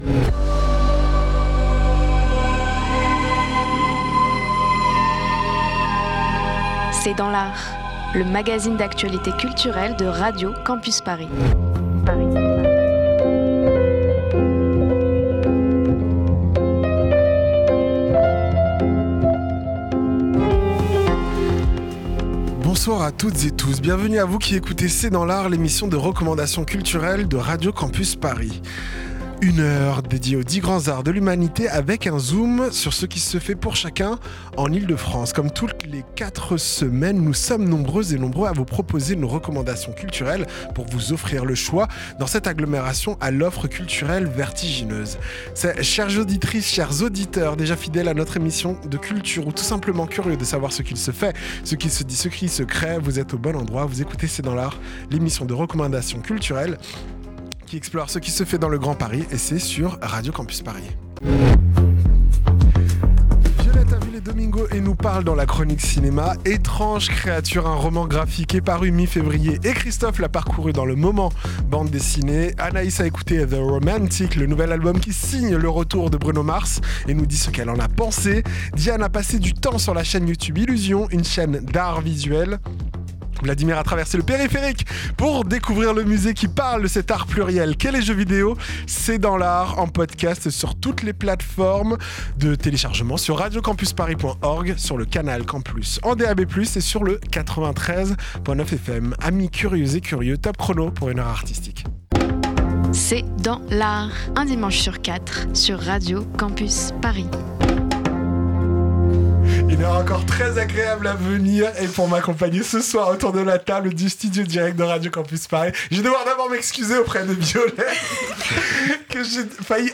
C’est dans l’art, l’émission de recommandation culturelle de Radio Campus Paris.